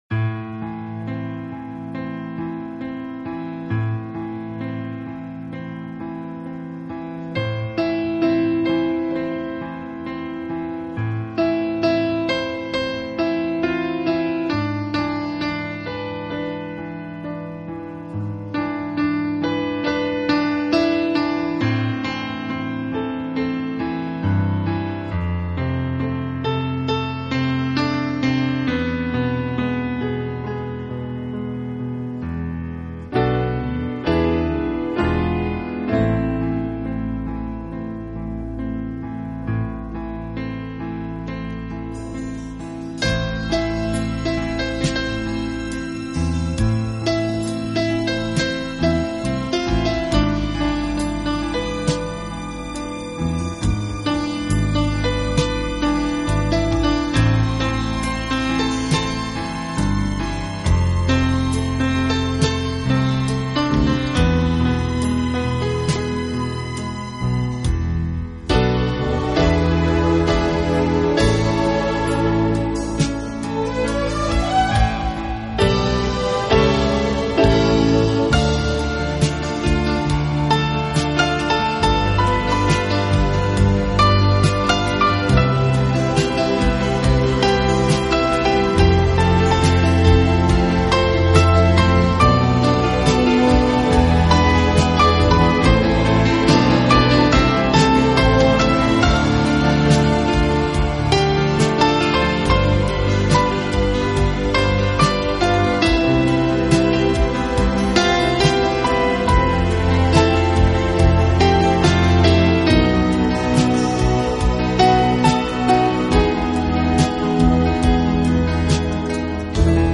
钢琴